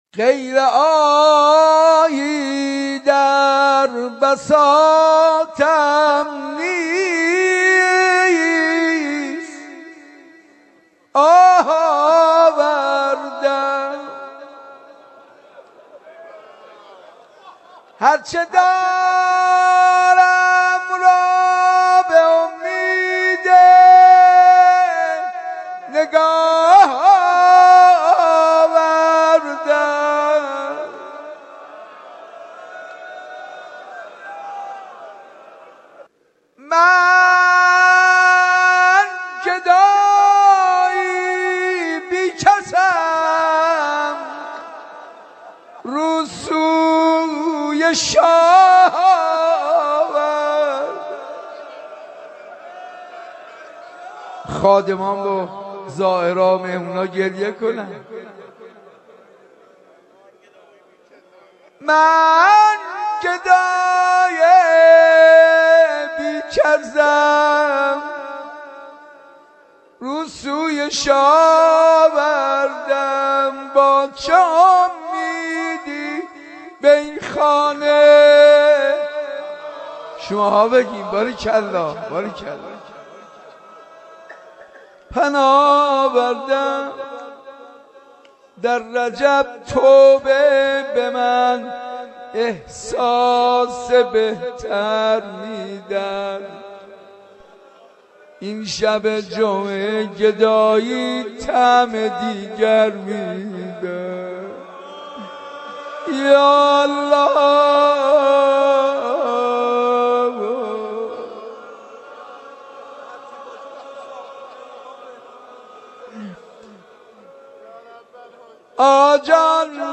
مناجات‌ ماه رجب
غزل مناجات شروع ماه رجب